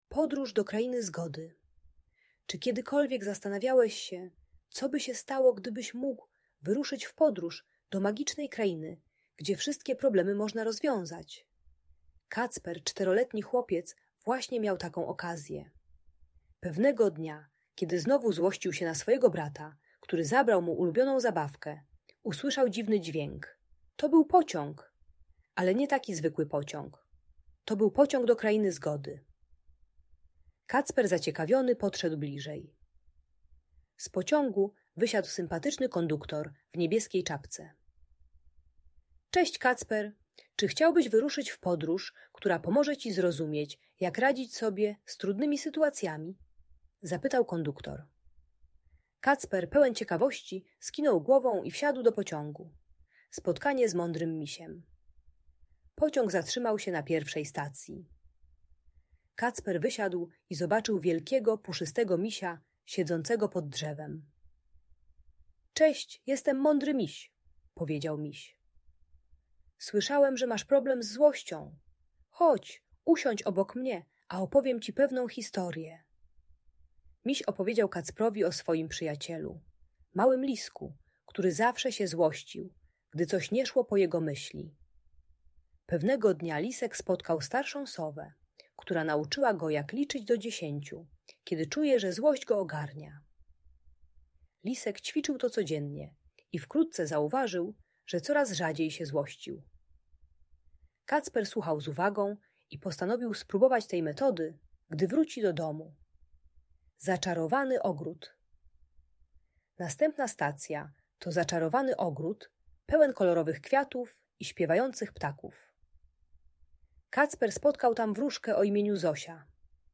Podróż do Krainy Zgody - Audiobajka